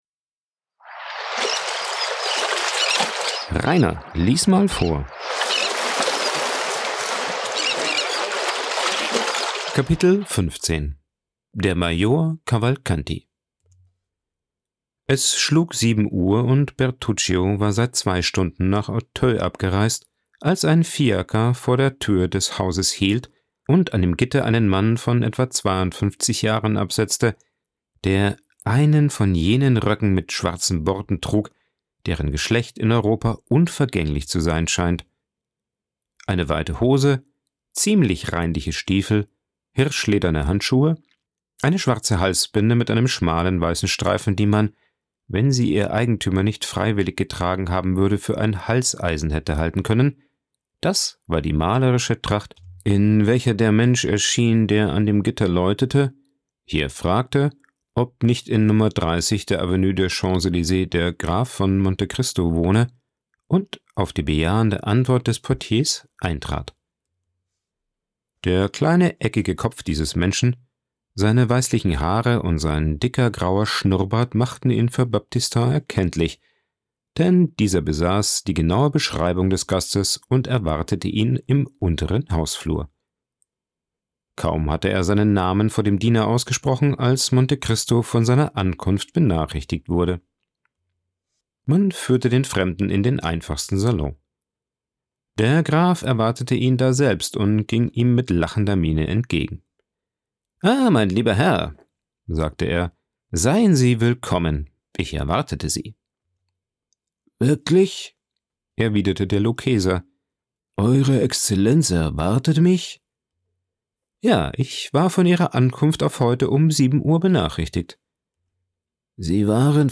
aufgenommen und bearbeitet im Coworking Space Rayaworx, Santanyí, Mallorca.
Hörbücher